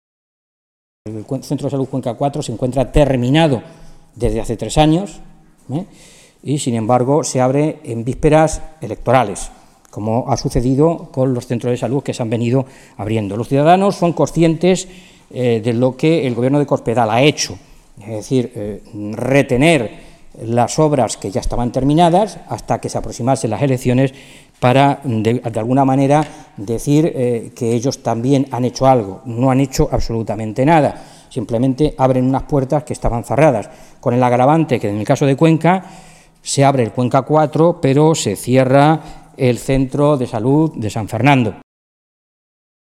Mora se pronunciaba de esta manera esta mañana, en Toledo, en una comparecencia ante los medios de comunicación en la que insistía en que ese decreto, que fue el recorte de los decretos en Sanidad, se había revelado profundamente injusto «fundamentalmente con aquellos pensionistas que cobran pensiones más bajas, las que no llegan a los 810 euros, que es la pensión media en Castilla-La Mancha».
Cortes de audio de la rueda de prensa